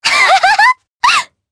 Sonia-Vox_Happy3_jp.wav